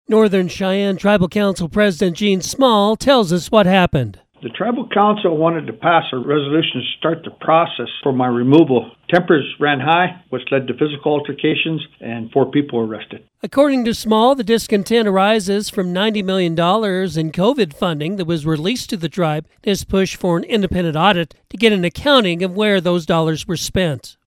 reports on a Northern Cheyenne Tribal Council meeting on Monday that turned chaotic.